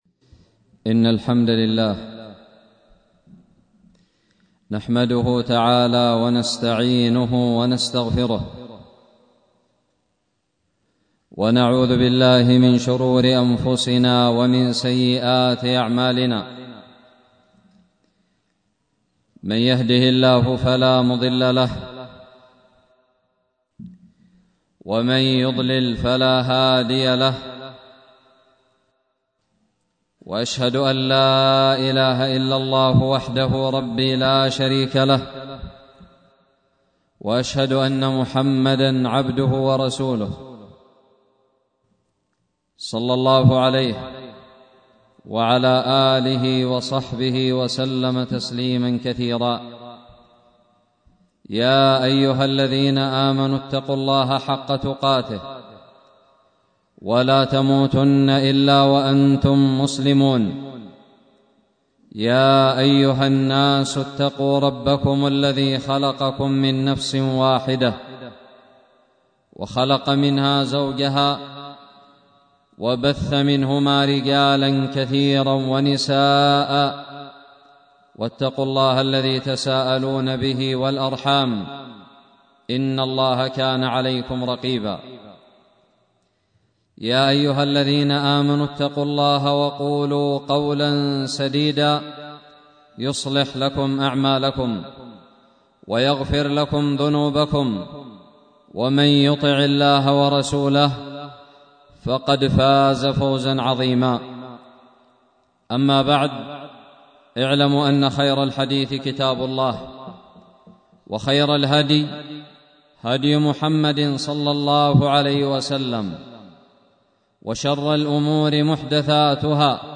خطب الجمعة
ألقيت بدار الحديث السلفية للعلوم الشرعية بالضالع في 1 صفر 1442هــ